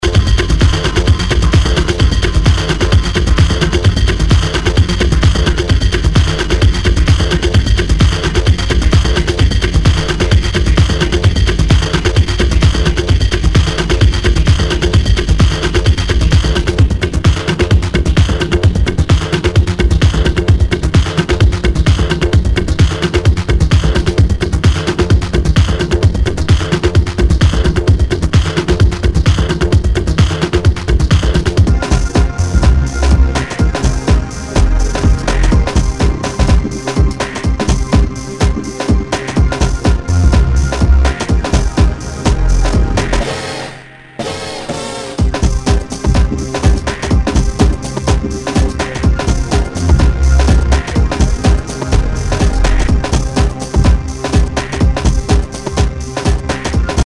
HOUSE/TECHNO/ELECTRO
ナイス！ディープ・テック・ハウス！